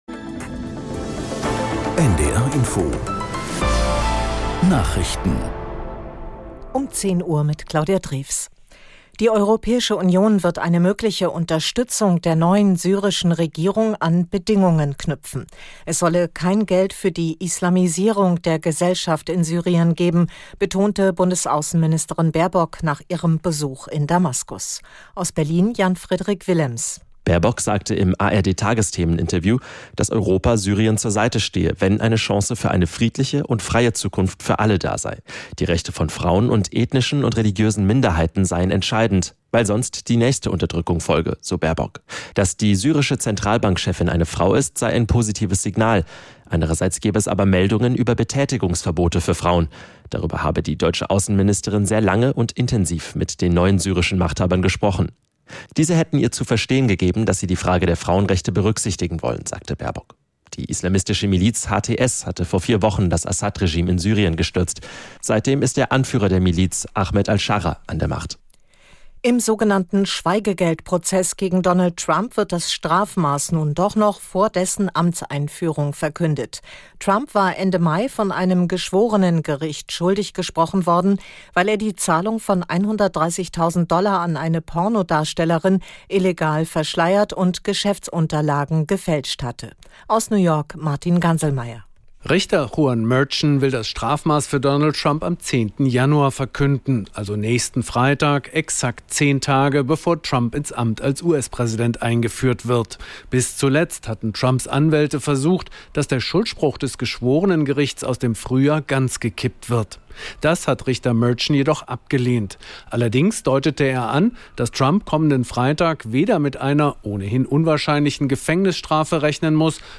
1 Nachrichten 4:19